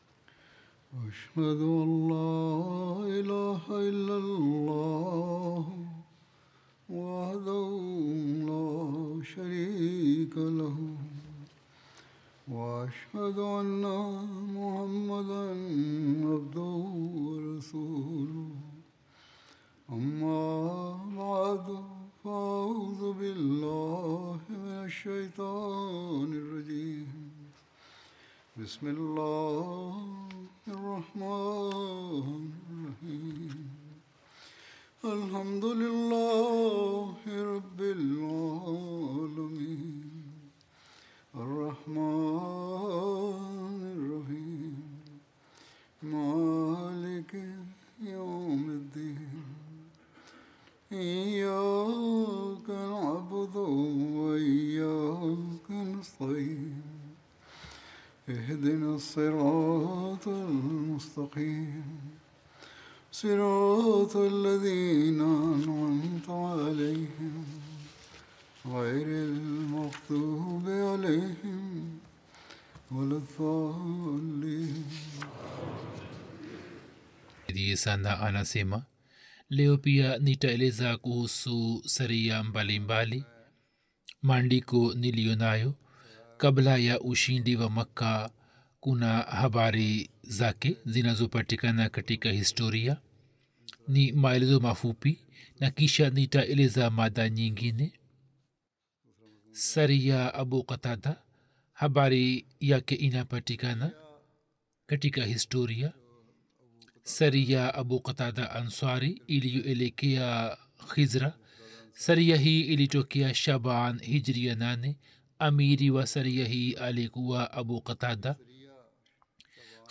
Swahili Translation of Friday Sermon delivered by Khalifatul Masih